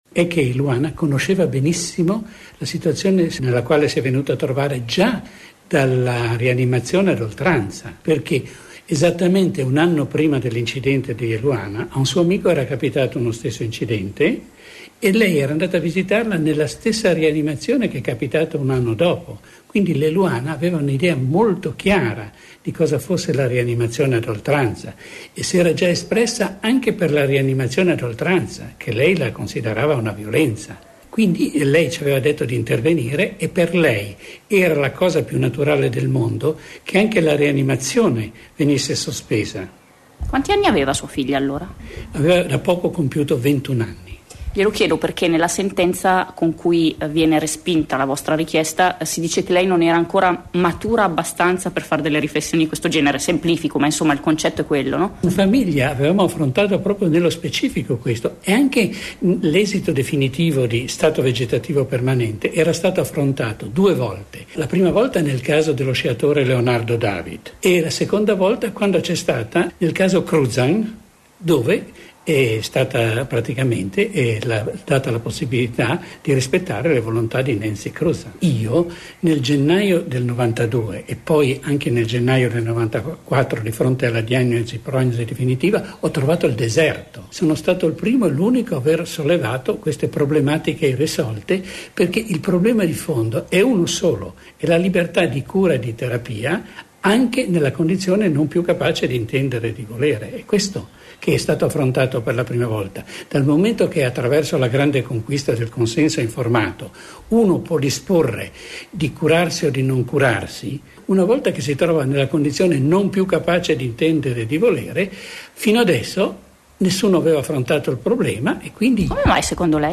Ascolta l’intervista. beppino-englaro-a-popolare